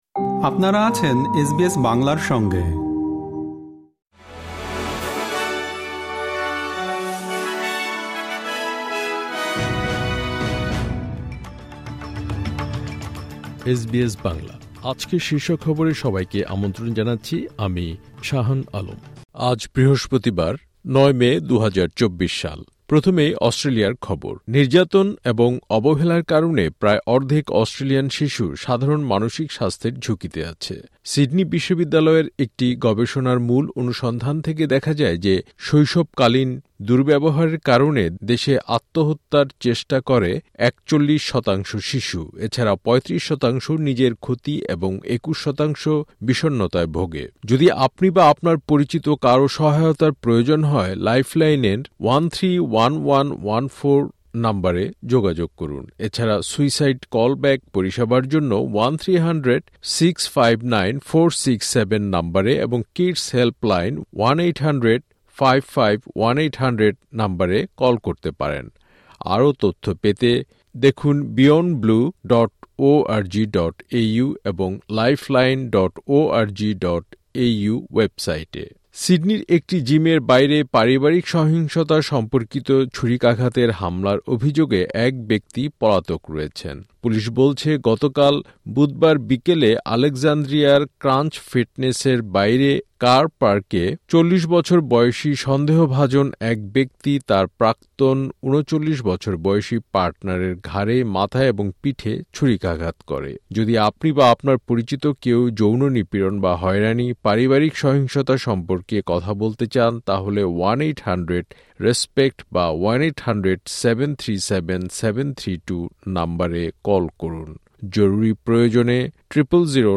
এসবিএস বাংলা শীর্ষ খবর: ৯ মে, ২০২৪